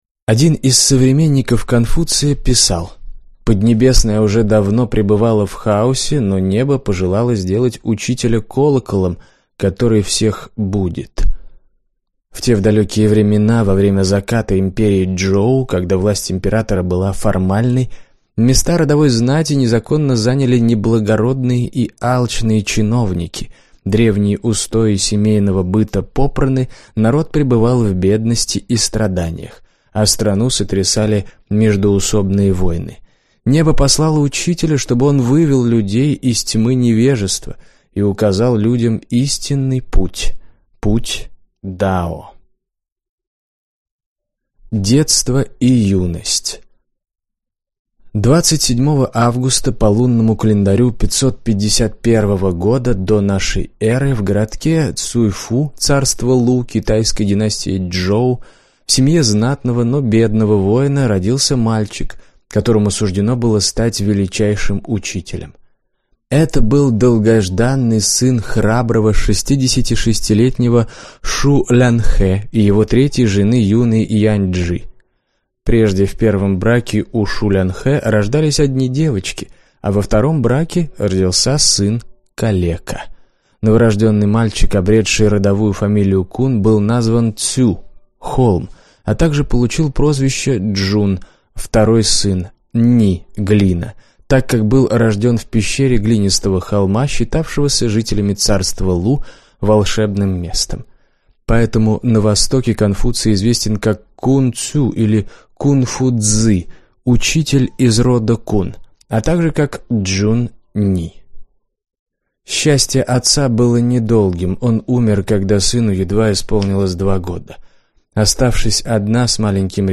Аудиокнига Конфуций о бизнесе | Библиотека аудиокниг